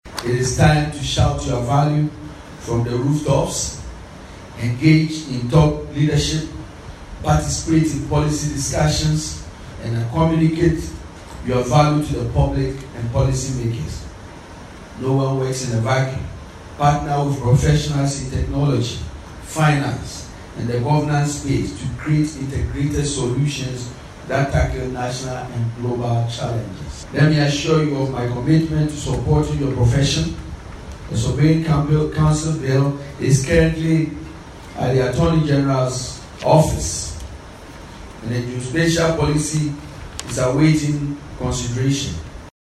The Minister for Lands and Natural Resources, Emmanuel Armah Kofi Buah, has emphasised the critical role of surveyors in Ghana’s development, urging them to embrace innovation and sustainability.
Speaking at the 20th Surveyors’ Week and the 56th Annual General Meeting (AGM) of the Ghana Institution of Surveyors, he highlighted the profession’s impact on urban planning, economic growth, and environmental protection.